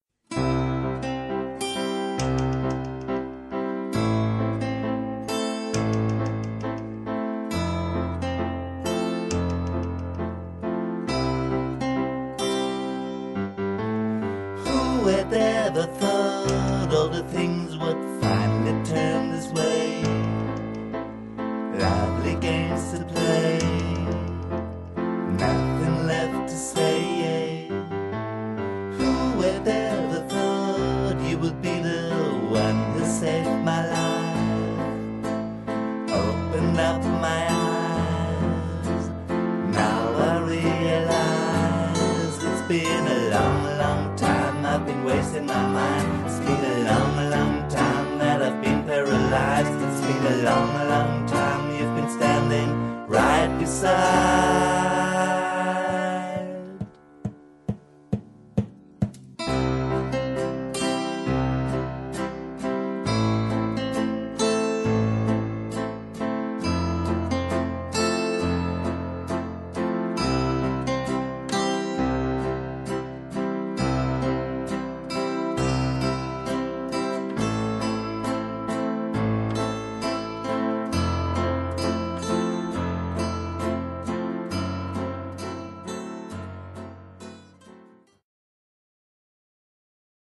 ld-voc., p.
voc., ac-g.